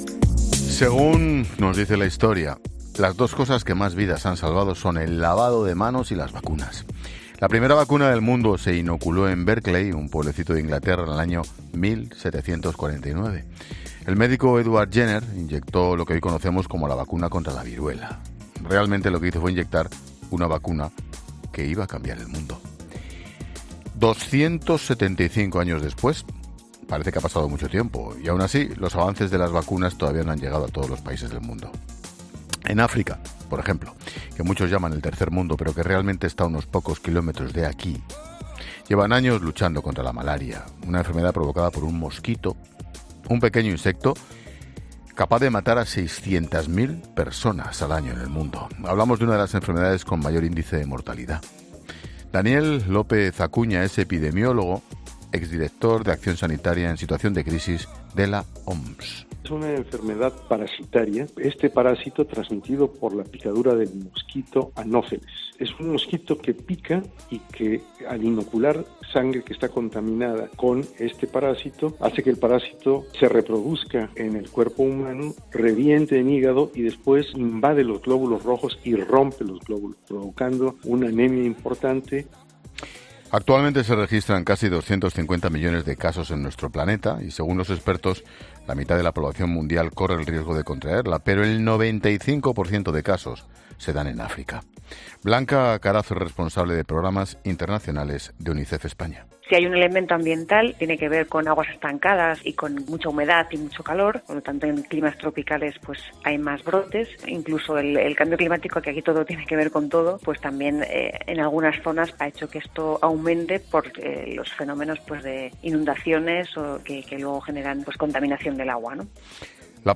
hablamos con los expertos